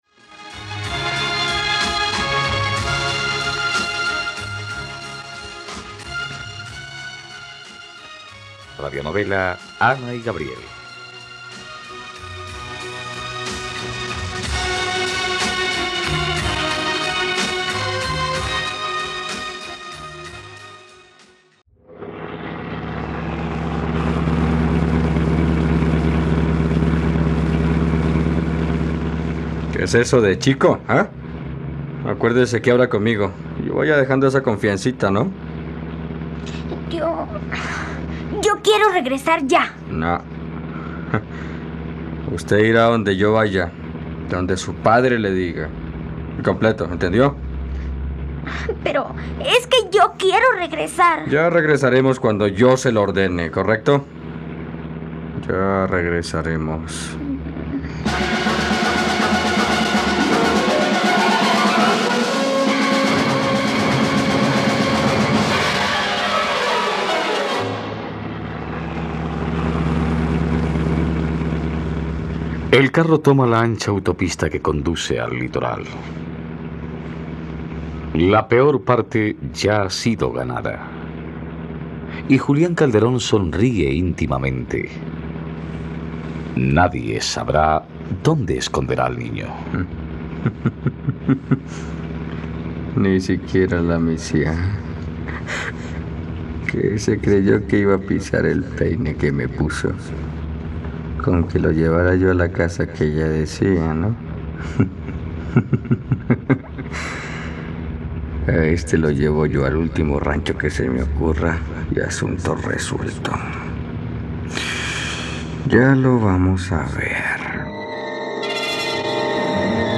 ..Radionovela. Escucha ahora el capítulo 85 de la historia de amor de Ana y Gabriel en la plataforma de streaming de los colombianos: RTVCPlay.